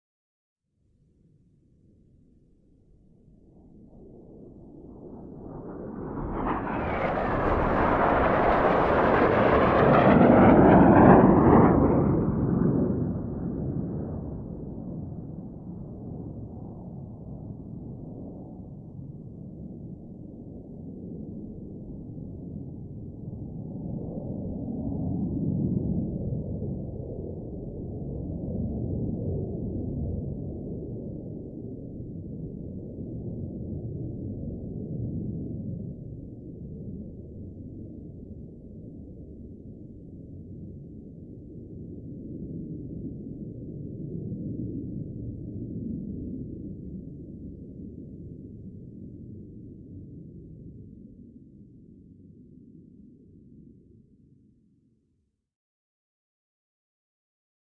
F-5: By Series Of 3: High; Formation Flyby, Slow. Active Rumbling Drone Of Jets Throughout. Medium To Distant Perspective. Jet.